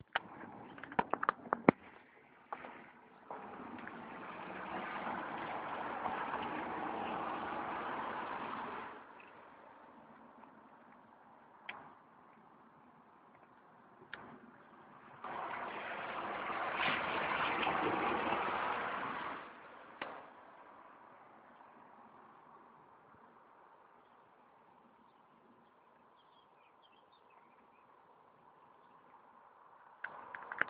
Voitures qui passent, chants d'oiseaux